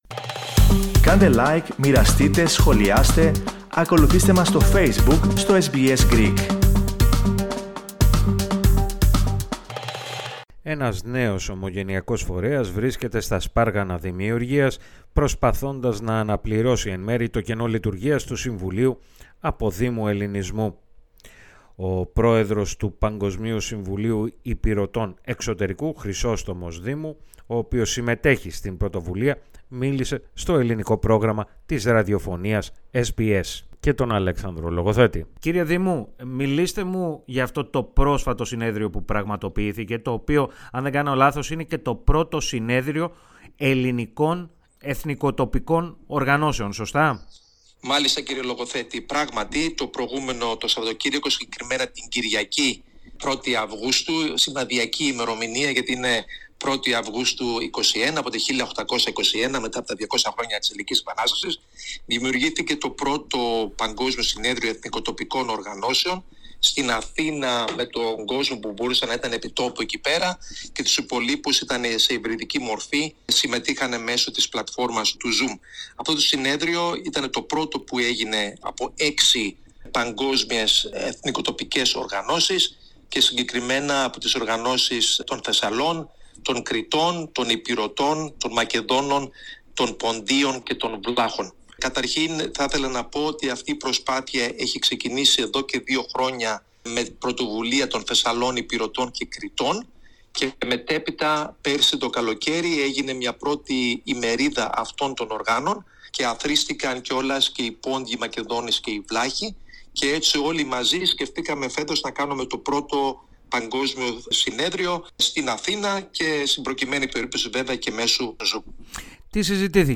μίλησε στο Ελληνικό Πρόγραμμα της SBS.